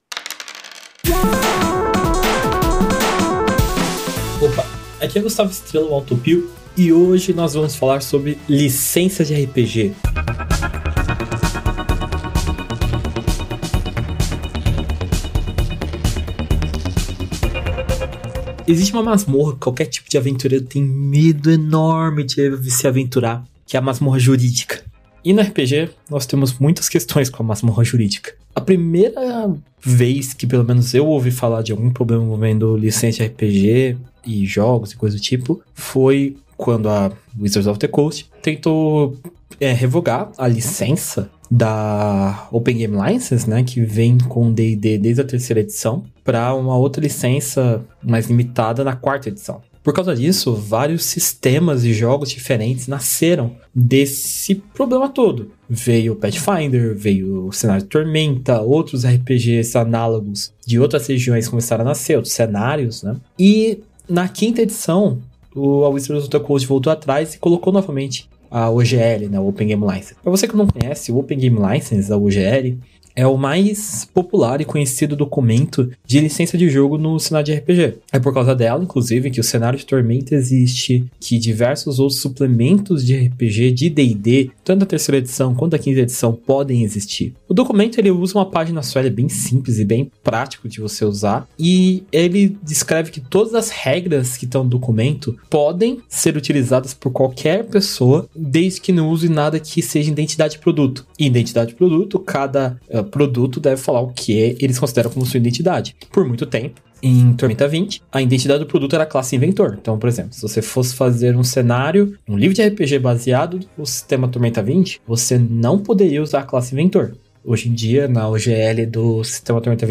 O Dicas de RPG é um podcast semanal no formato de pílula que todo domingo vai chegar no seu feed.
Músicas: Music by from Pixabay